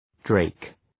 Shkrimi fonetik {dreık}